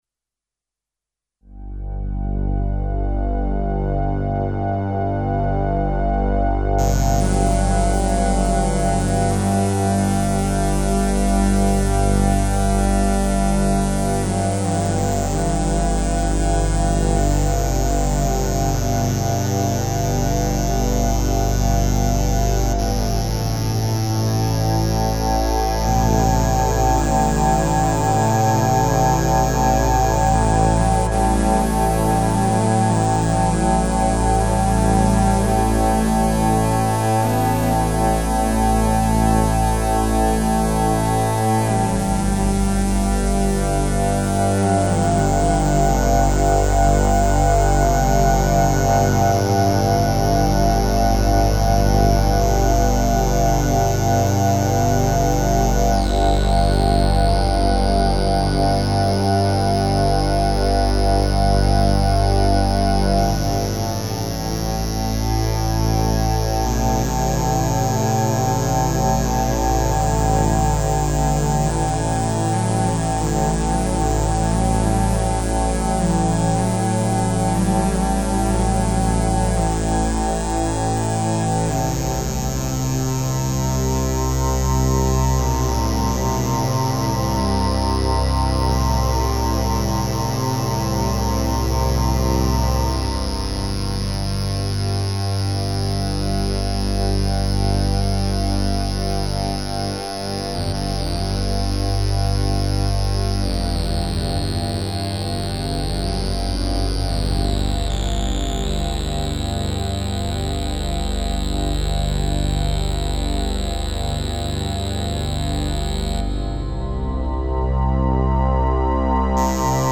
fun with sawtooth and square waves